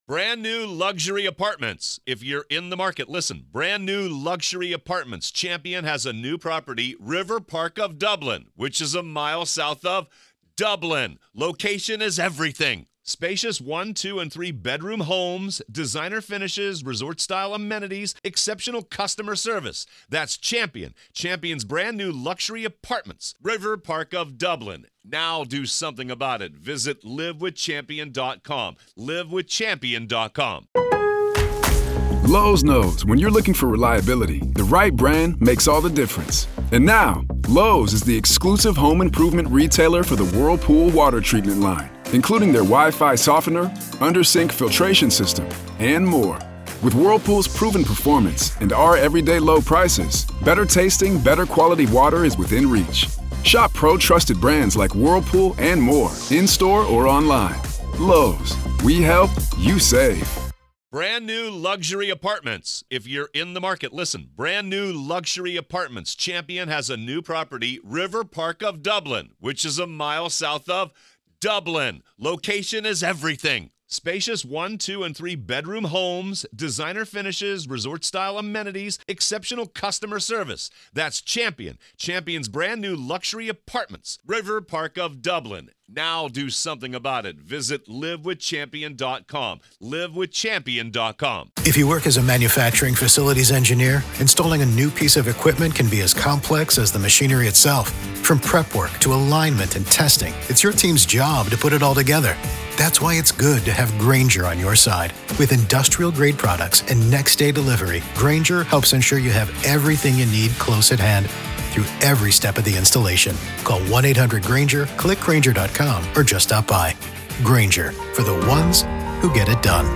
The Trial Of Alex Murdaugh | FULL TRIAL COVERAGE Day 17 - Part 3